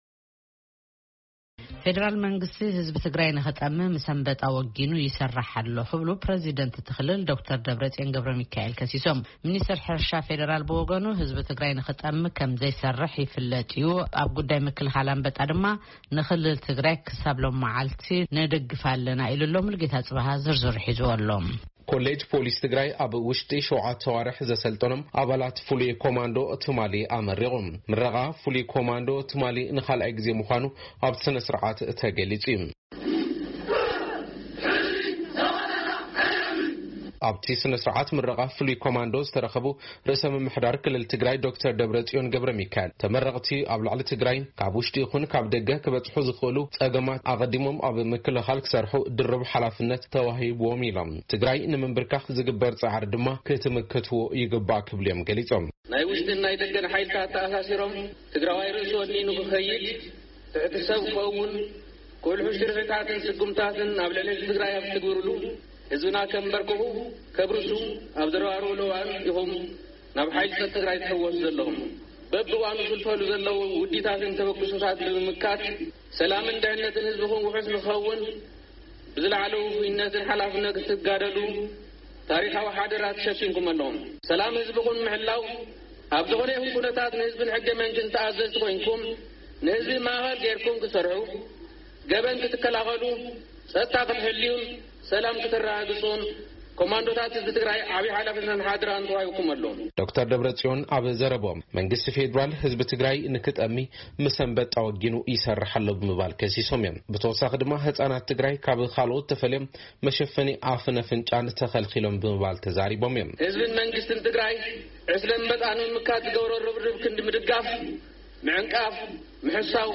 ዶክተር ደብረፅዮን እዚ ዝበሉ ካልኣይ ዙር ፍሉይ ኮማንዶ ትግራይ ትማሊ ኣብ ዝተመረቕሉ እዋን እዩ:: ሙሩቓት ኮማንዶ ሰላም እቲ ክልል ኣብ ምሕላው ክሰርሑ እቶም ፕረዚደንት መልአኽቲ ኣሕሊፎም::